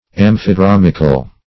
Search Result for " amphidromical" : The Collaborative International Dictionary of English v.0.48: Amphidromical \Am`phi*drom"ic*al\, a. [Gr.
amphidromical.mp3